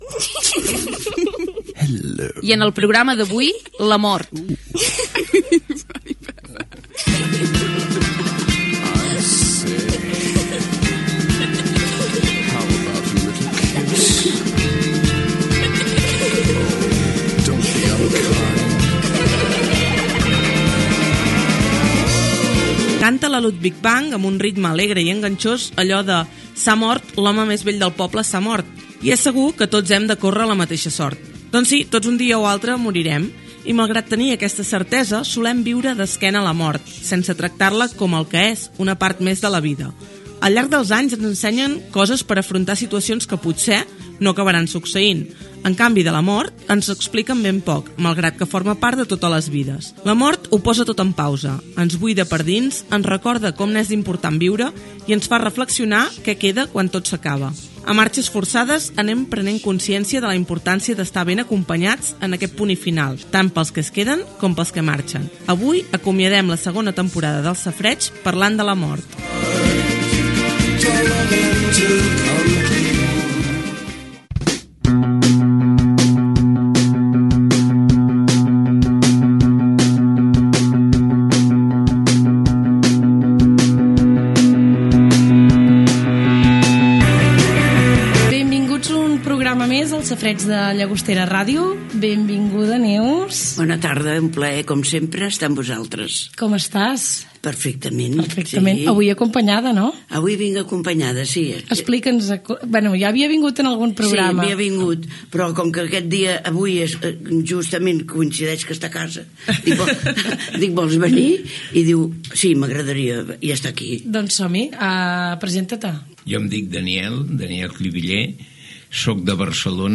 Fragment de l'últim programa de la segona temporada d'emissió dedicat a la mort. Presentació del tema i dels invitats.
Entreteniment
FM